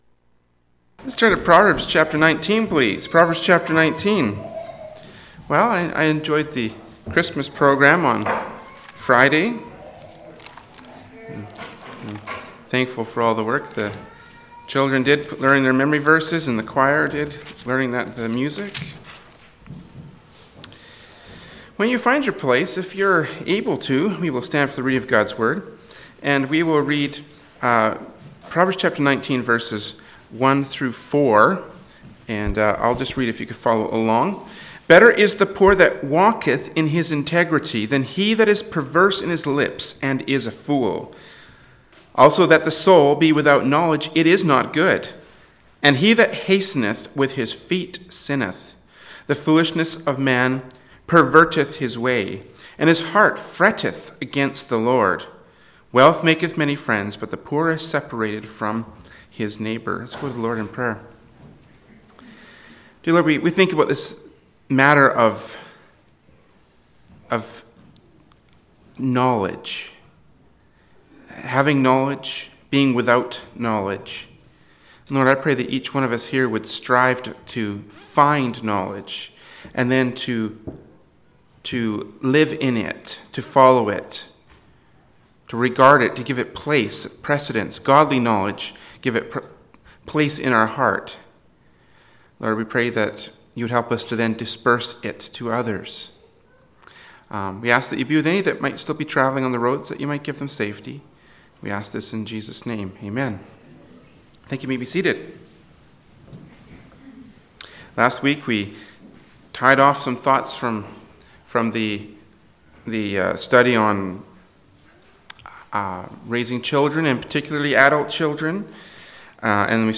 “Proverbs 19:1-4” from Sunday School Service by Berean Baptist Church.